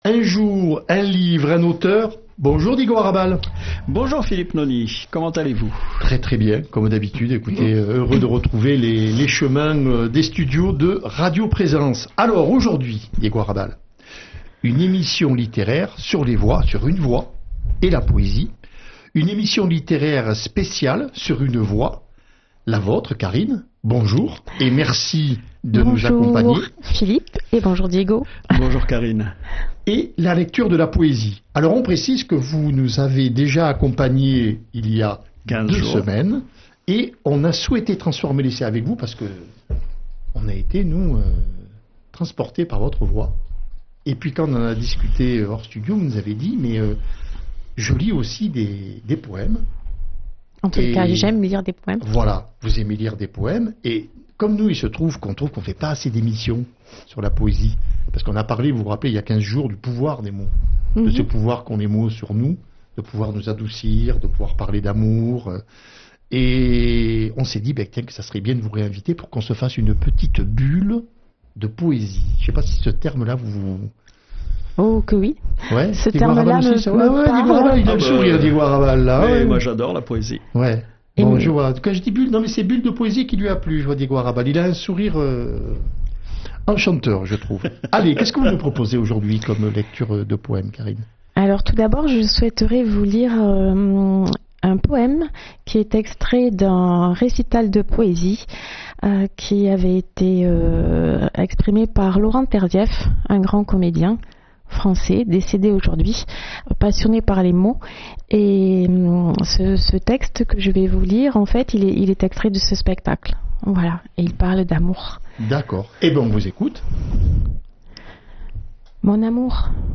Un jour, un livre, un auteur \ Lectures de poésies